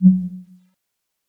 Select_Secondary.wav